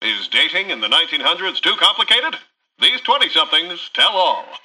Newscaster_headline_21.mp3